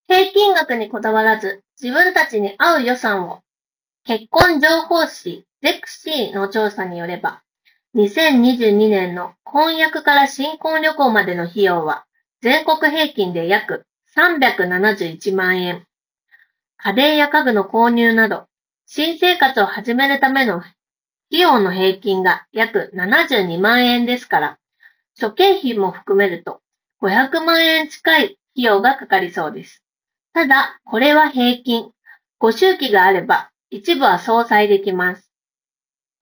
高品質ノイズリダクションマイク内蔵
ノイズリダクション効果は高く、周囲が少々騒がしい環境でも、装着者の声をクリアに拾い上げて相手側に伝えることができていた。
さすがに専用のハイエンドマイクと比べると録音品質は劣るものの、普通に通話するには十分に優れた性能となっている。特に装着者の発言内容が強調されており、非常に聴き取りやすい。
▼Shokz OpenDots ONEの内蔵マイクで拾った音声単体
通話だけでなく、オンライン会議などにも利用できるだけの品質となっている。